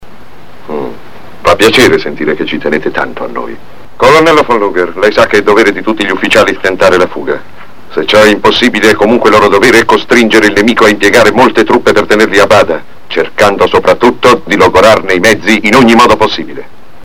in cui doppia James Donald.